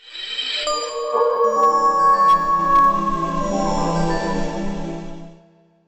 Wolf_00.wav